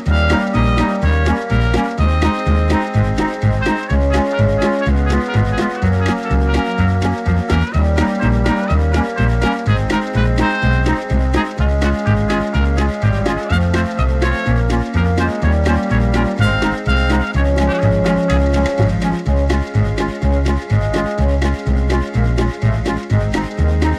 No Ukulele Oldies (Male) 3:05 Buy £1.50